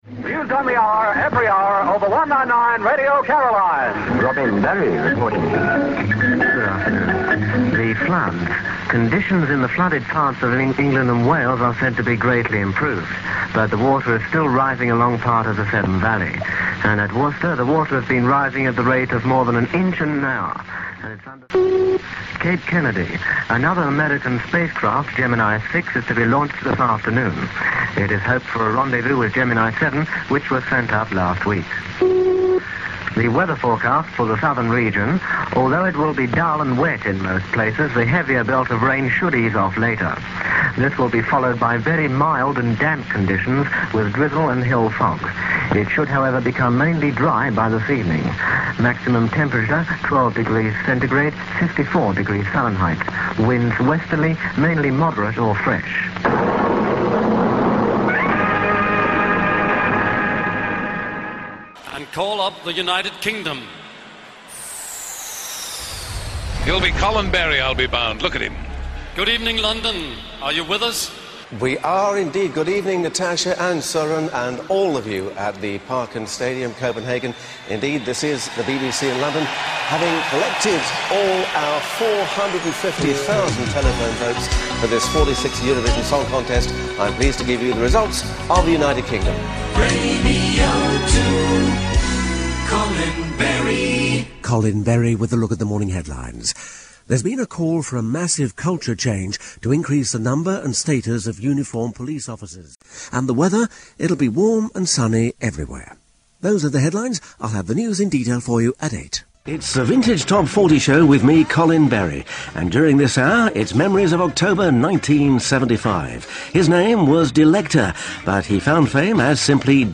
What a voice.
He is the voice of Blighty.